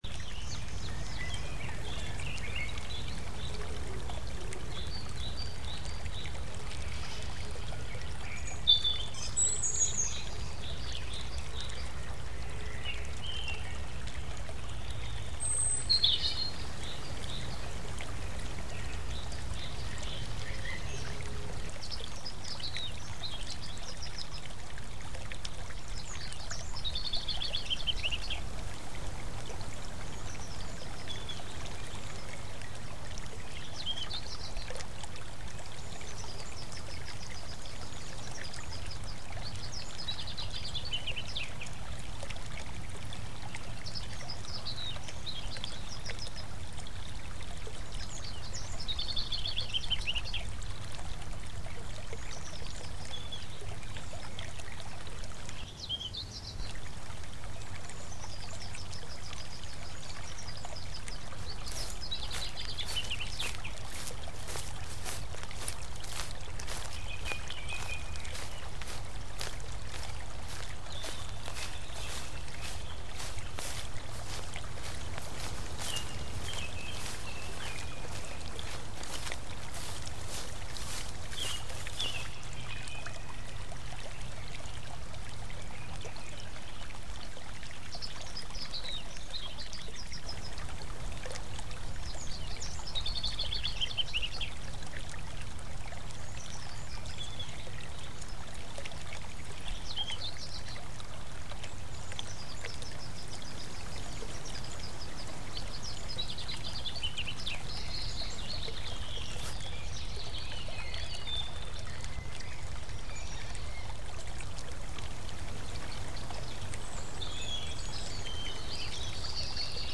Garden